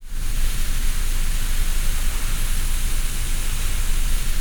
PINK NOISE.wav